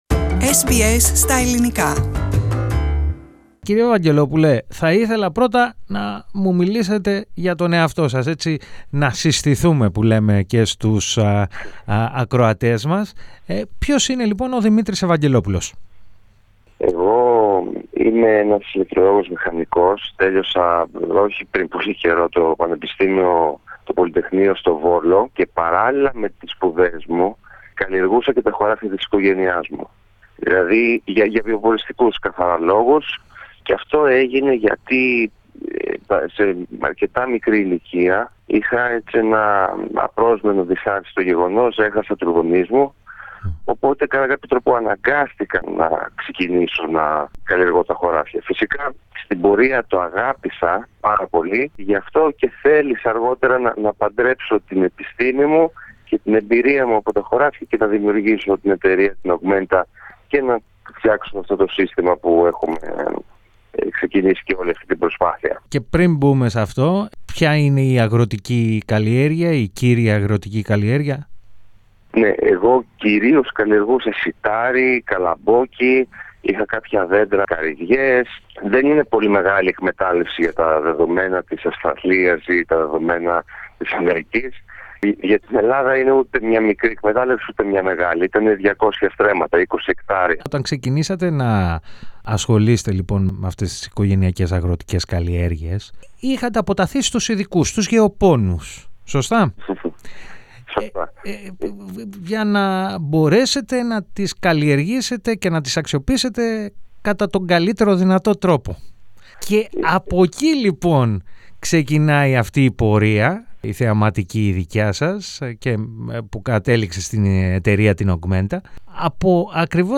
Πατήστε Play στο Podcast που συνοδεύει την κεντρική φωτογραφία για να ακούσετε τη συνέντευξη.